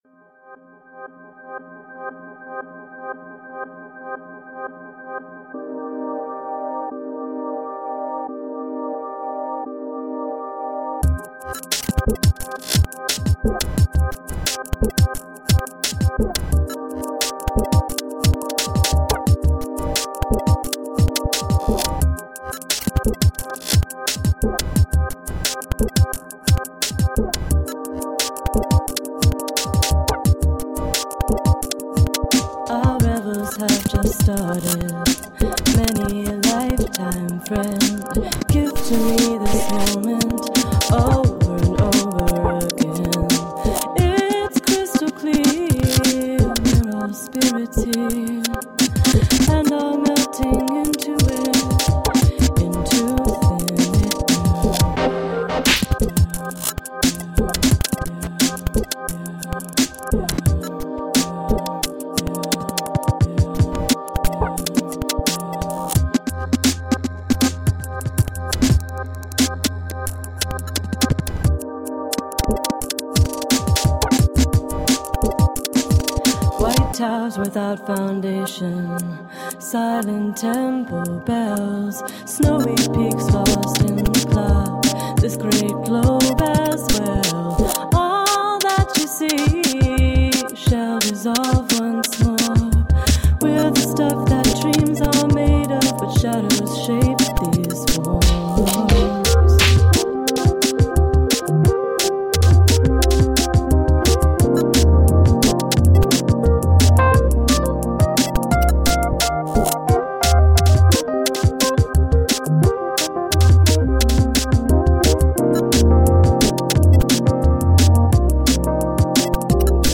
Alternative / downtempo / electro-pop.